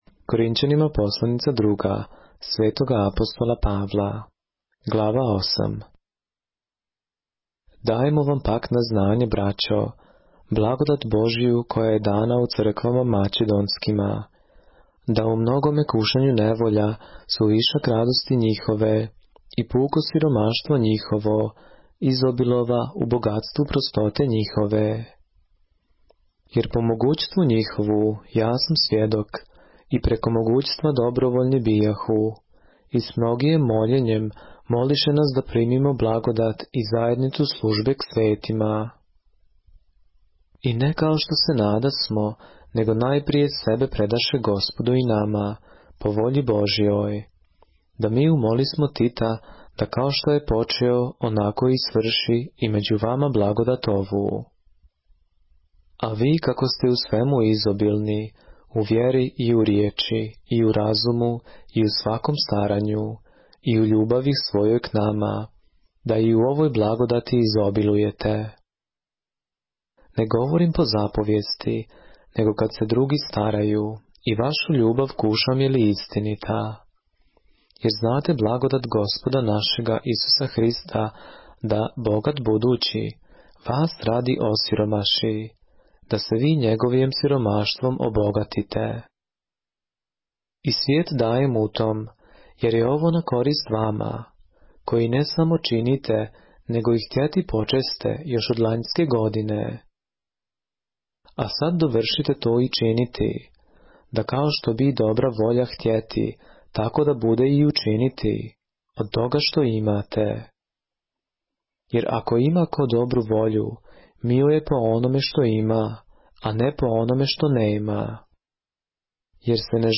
поглавље српске Библије - са аудио нарације - 2 Corinthians, chapter 8 of the Holy Bible in the Serbian language